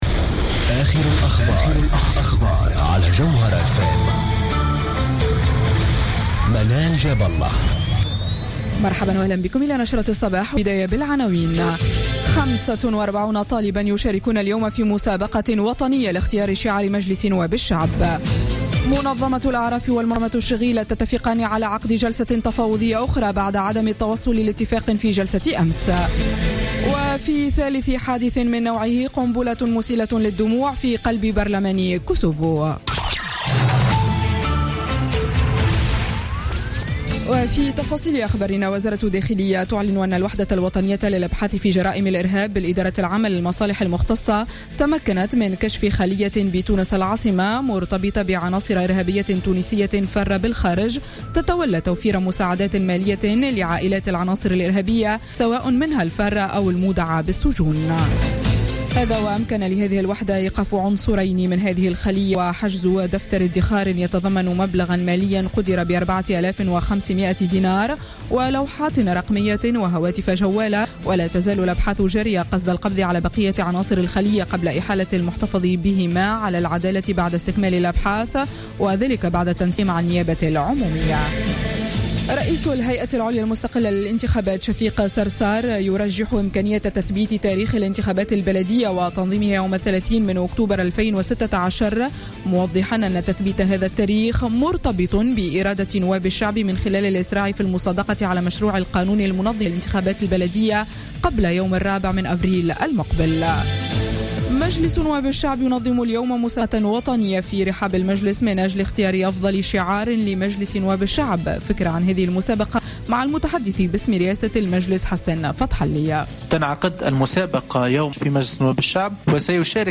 نشرة أخبار السابعة صباحا ليوم السبت 24 أكتوبر 2015